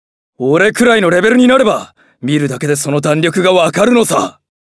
オリジナル音響ドラマ(ボイスドラマ)第8弾 LOST GARDEN[ロストガーデン]/HEAVENLY BLUE
サンプルボイス/『ユズ』 沢木 柚俊 (さわきゆずとし)/LOST GARDEN[ロストガーデン]